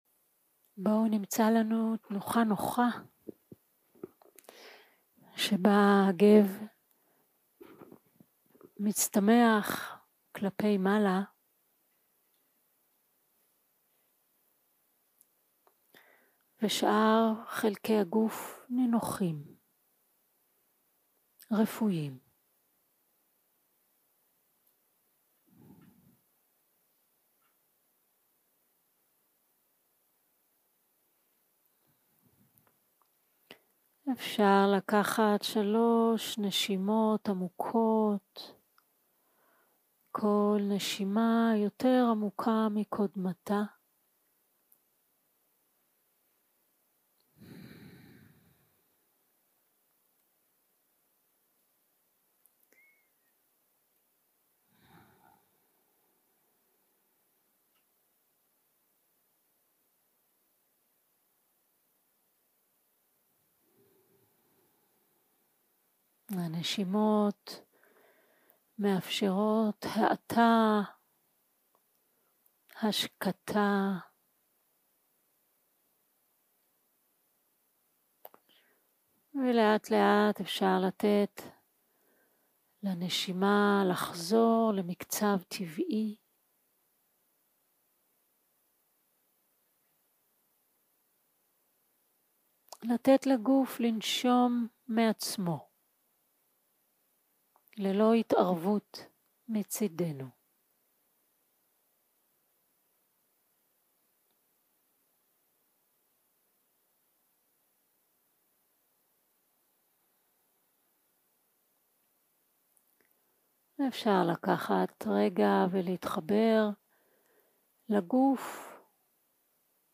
יום 1 - הקלטה 1 - ערב - מדיטציה מונחית
Dharma type: Guided meditation